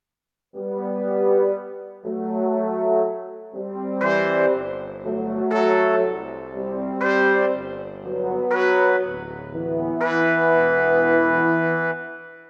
Sección de metales (orquesta sinfónica) Breve pieza ejemplo.
aerófono
tuba
trompeta
trompa
trombón
metal
orquesta
sintetizador